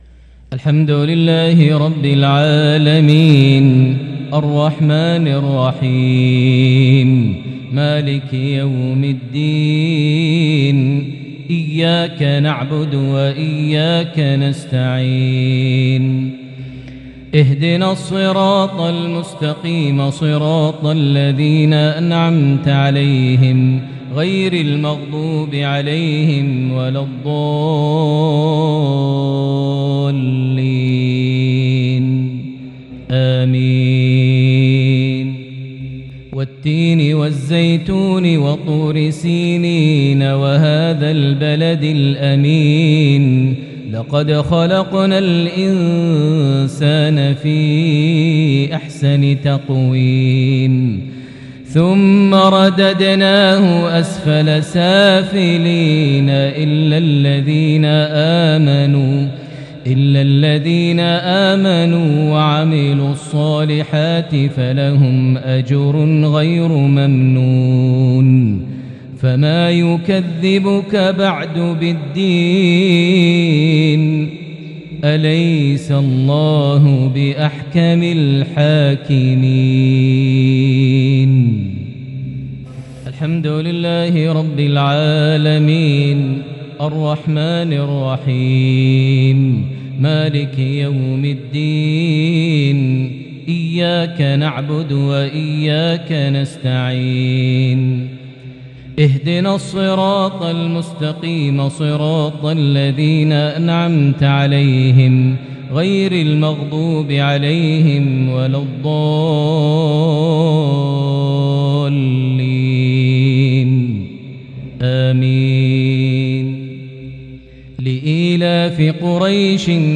maghrib 1-2-2022 prayer from Surah At-Tin + Surah Quraish > 1443 H > Prayers - Maher Almuaiqly Recitations